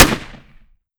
7Mag Bolt Action Rifle - Gunshot A 002.wav